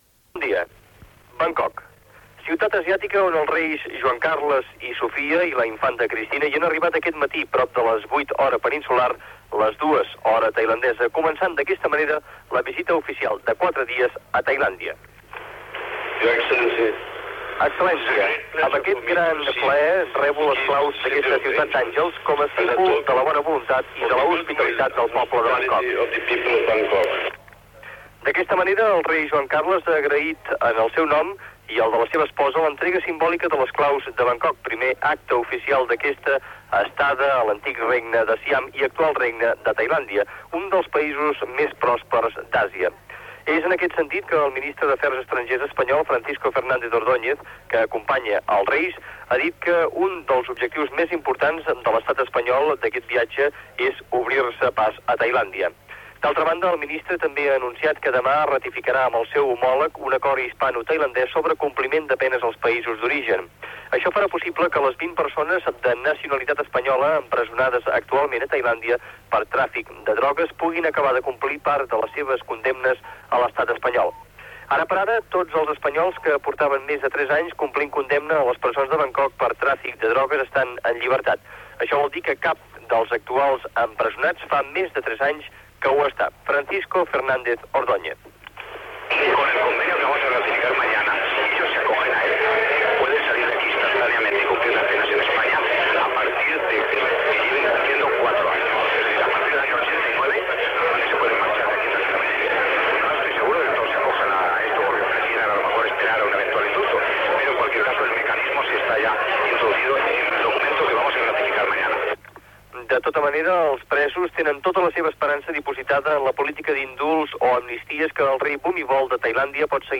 Informació, des de Bangkok, de l'inici de la visita dels reis d'Espanya Juan Carlos I i Sofia a Tailàndia
Informatiu